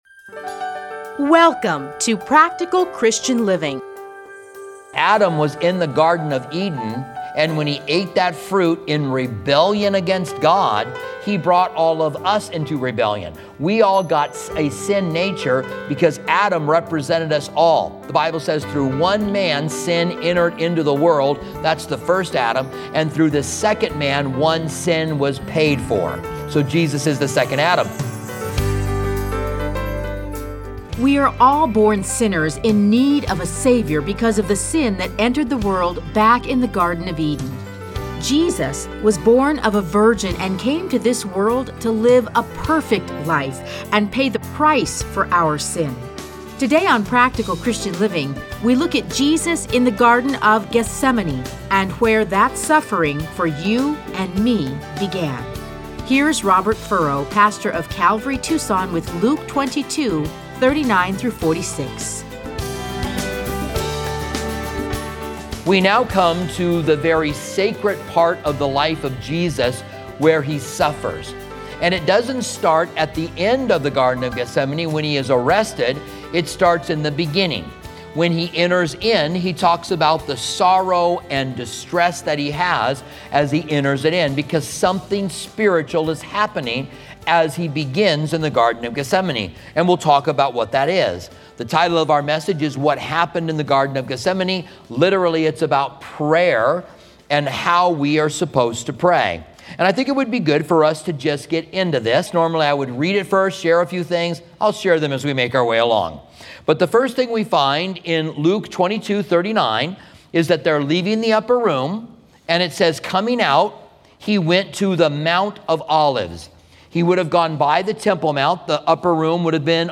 Listen to a teaching from Luke 22:39-46.